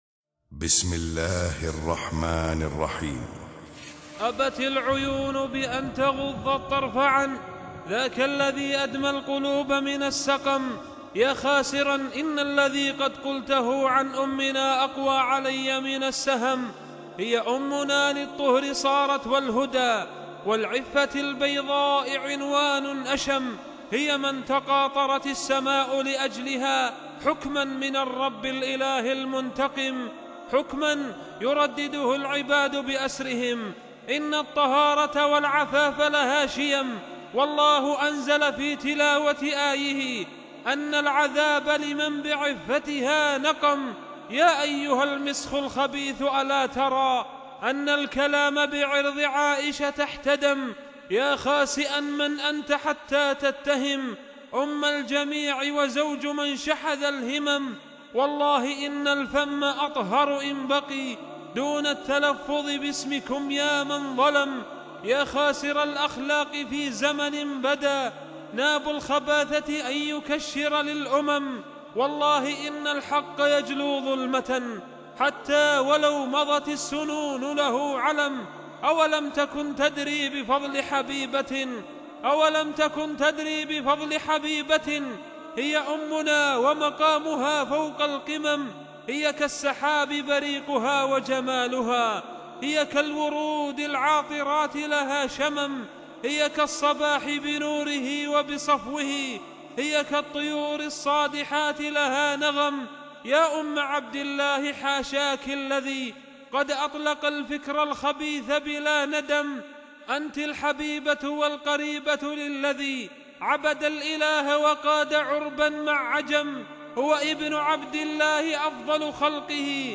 فإليكم القصيدة صوتية من كلماته وآدائه ..